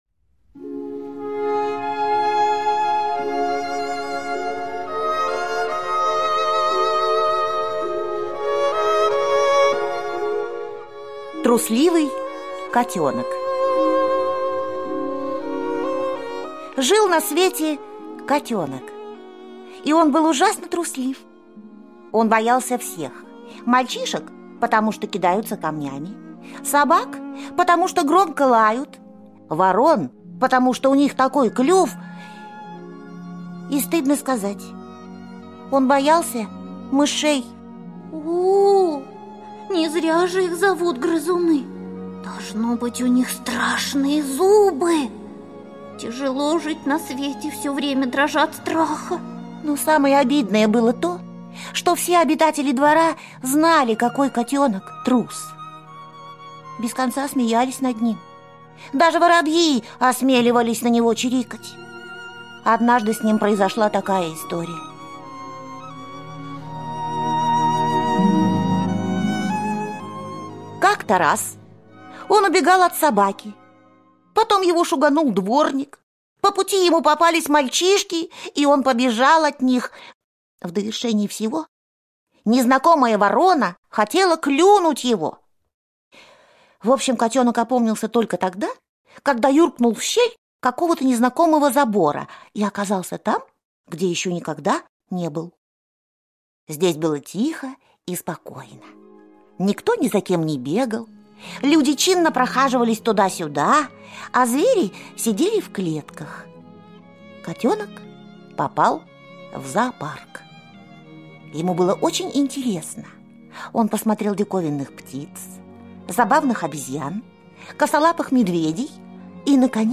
Аудиосказка «Трусливый котенок»